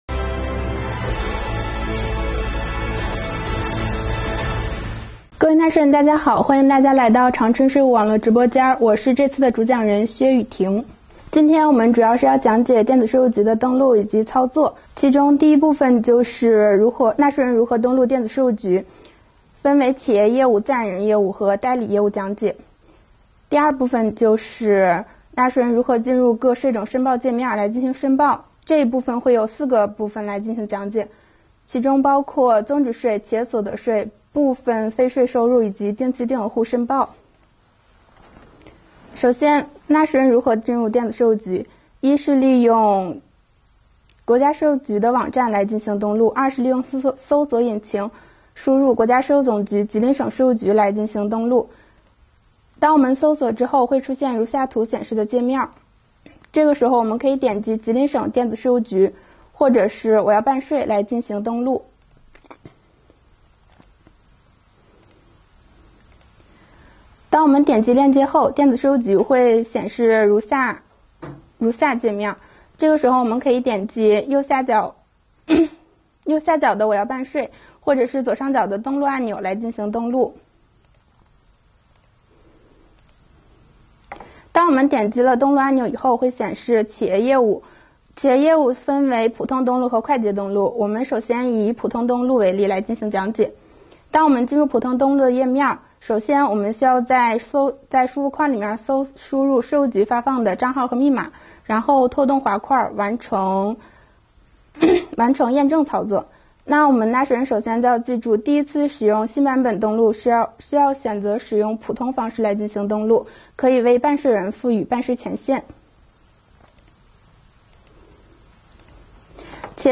2021年第27期直播回放：电子税务局登录操作讲解——纳税人登录方法及进入申报界面